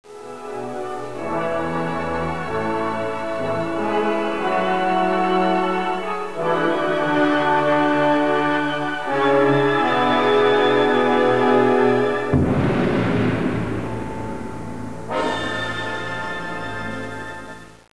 なお、音質は著作権への配慮で、ノイズを付加したものです。
頂点(388K)を迎えた後、再現部は無く、いきなりコーダを迎えます。コーダの最後には、長短移行和音を伴ったティンパニーの動機によるfff→pppがあり、低音弦のピチカートで静かに全曲を終結します。